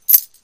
coin.1.mp3